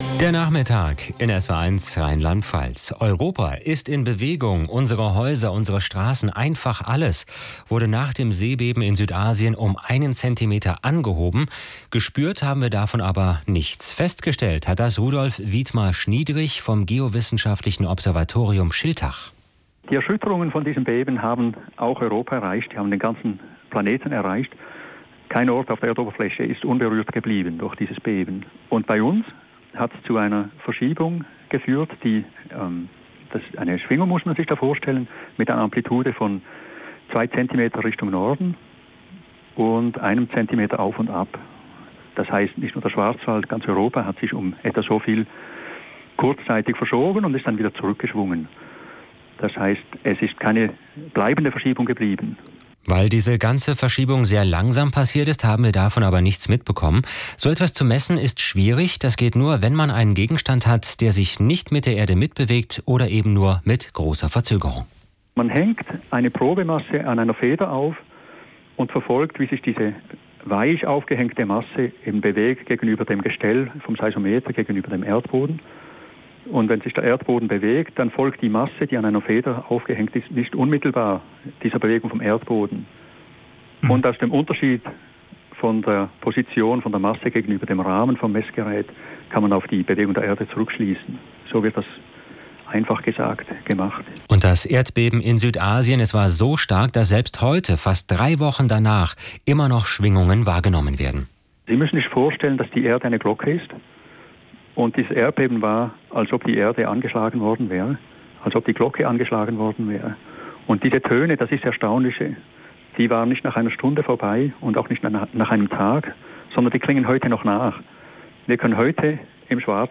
Im Rundfunk...